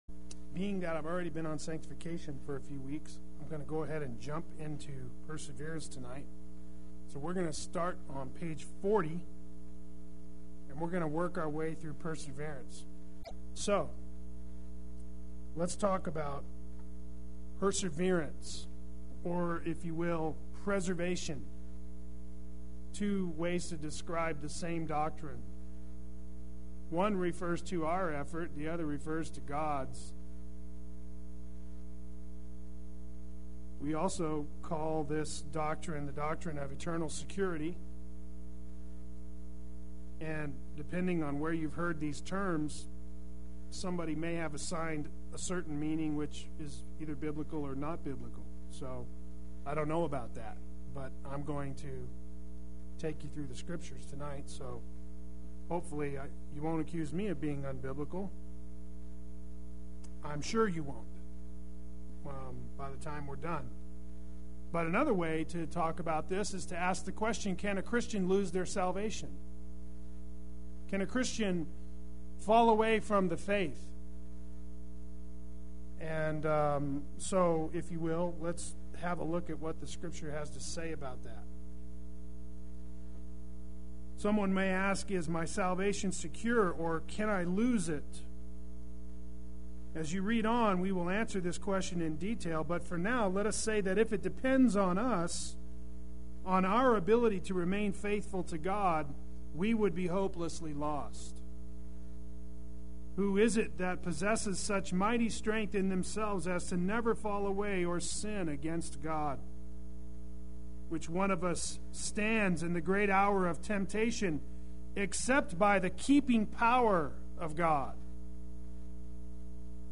Play Sermon Get HCF Teaching Automatically.
Perseverance Wednesday Worship